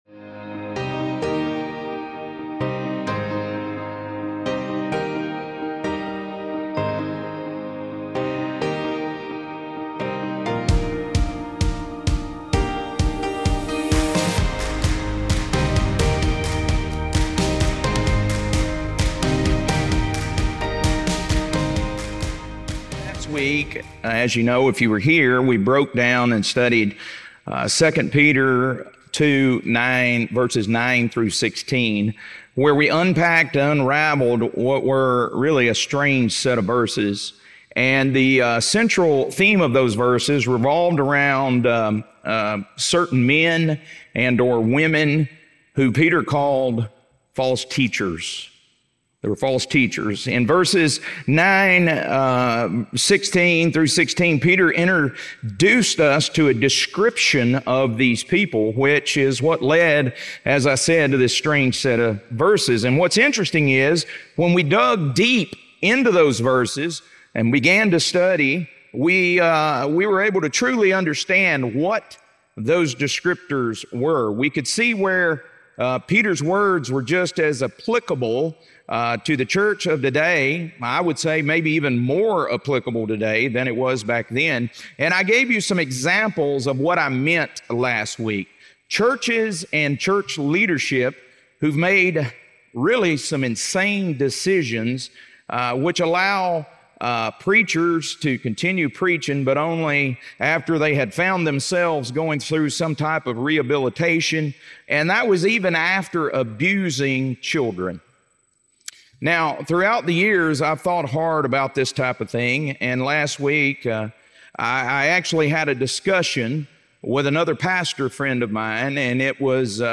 2 Peter - Lesson 2E | Verse By Verse Ministry International